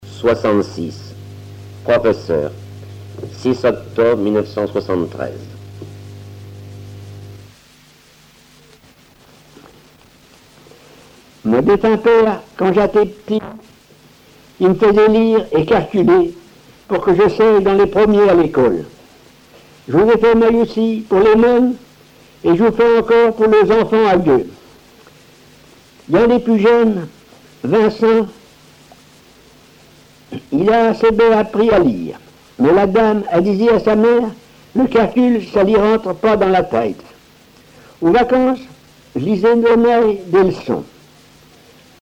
Langue Patois local
Genre récit
Récits en patois